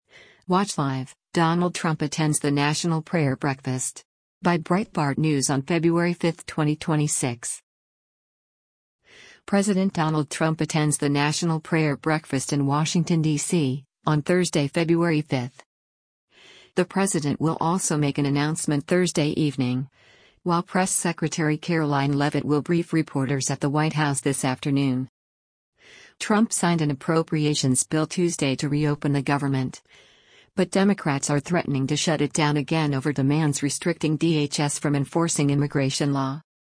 President Donald Trump attends the National Prayer Breakfast in Washington, DC, on Thursday, February 5.